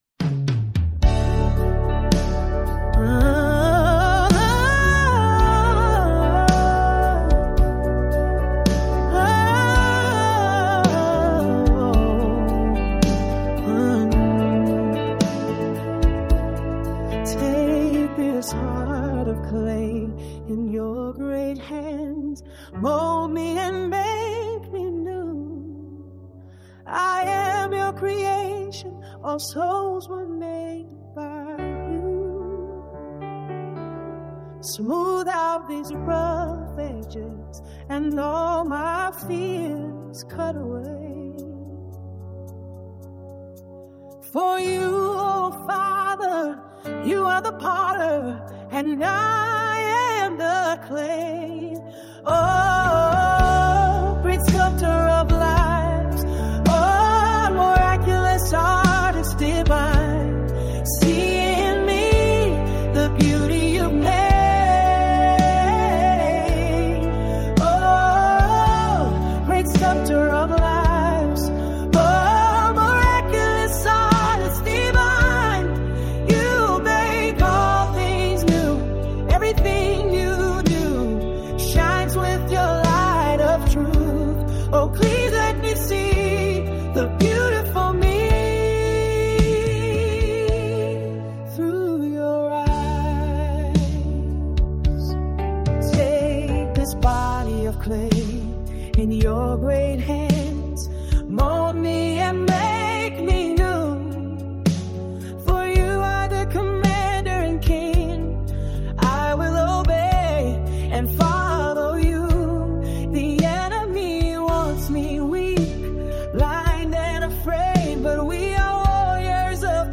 It's an app that can make musical demos of your song lyrics.
Gosh the voice sounds so real.
Ballad with a gospel flavor to it.
Yess AI, same app I've been using.